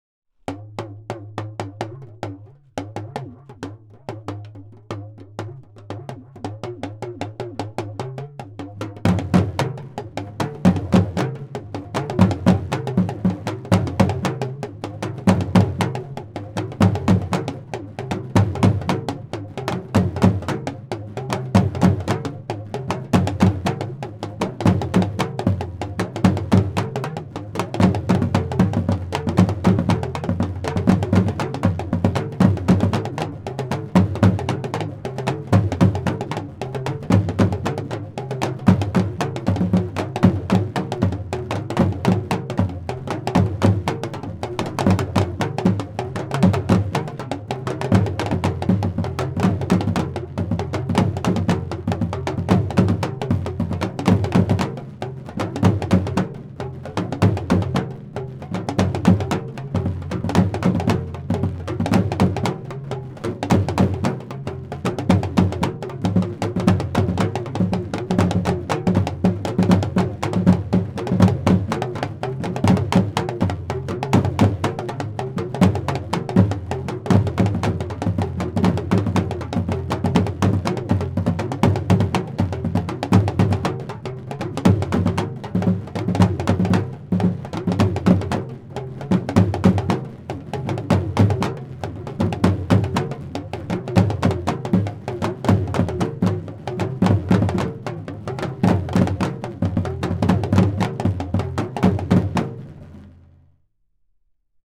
Dogu Tuli drumming ensemble audio
African drumming Dagomba drumming Talking drums Ghanian music